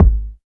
87 FLAT KICK.wav